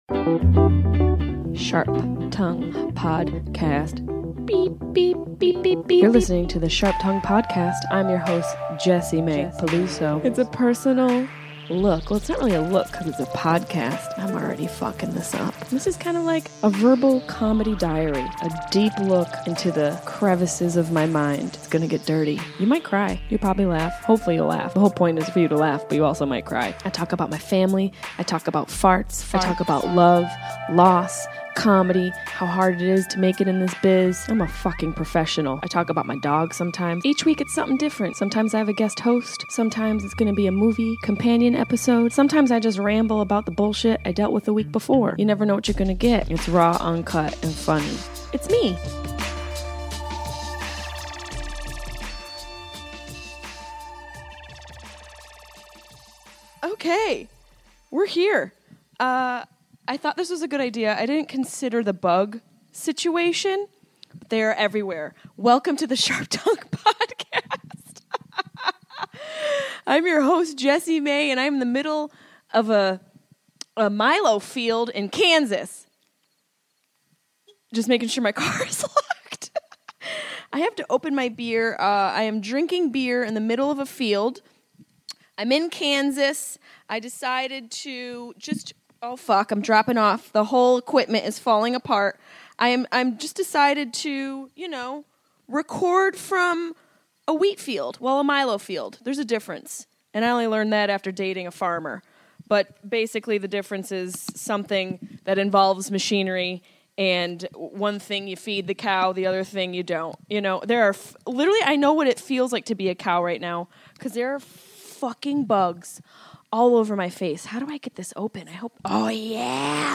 Death doesn't have to be so horrific. This week Jessimae comes to you from a milo field in Kansas to discuss the struggles of losing a parent, ways to process grief, and how to survive when someone you love is dying.